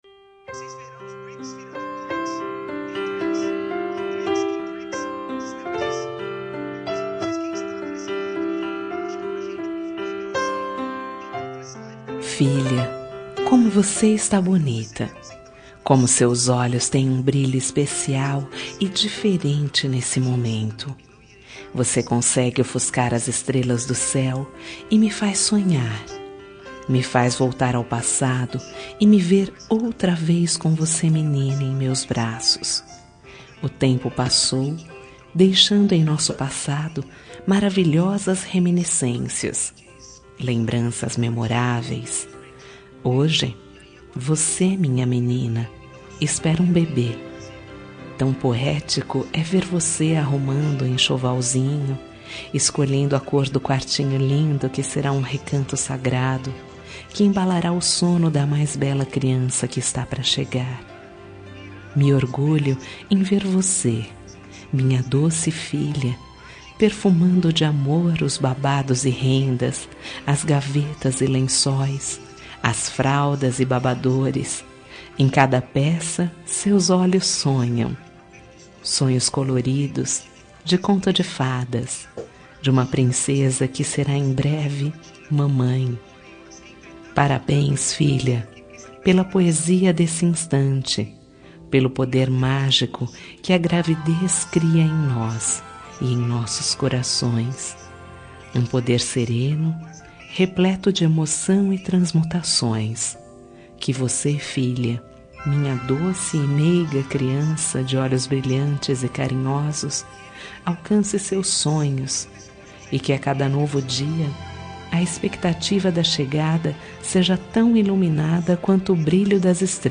Telemensagem para Gestante – Mãe para filha – Voz Feminina – Cód: 6630